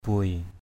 /bʊeɪ/ ~ /bʊɪ/